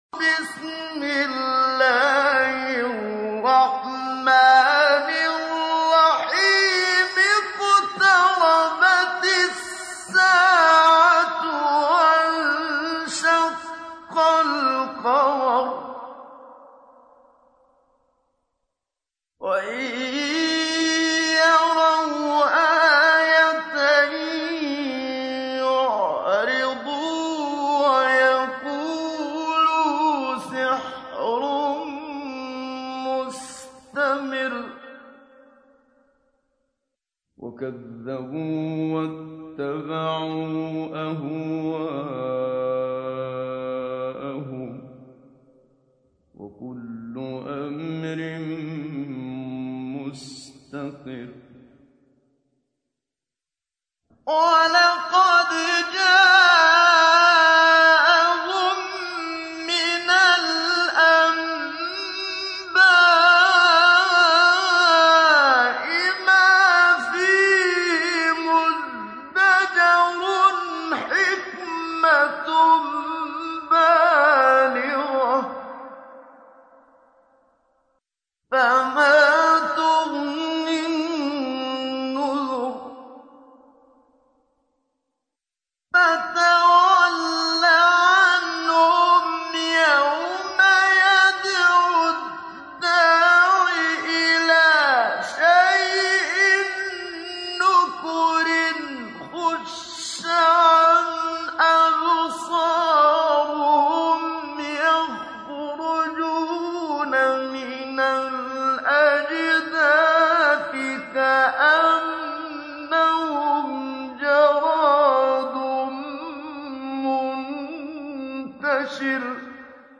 تحميل : 54. سورة القمر / القارئ محمد صديق المنشاوي / القرآن الكريم / موقع يا حسين